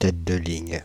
Ääntäminen
France (Île-de-France): IPA: /tɛ̃t də liɲ/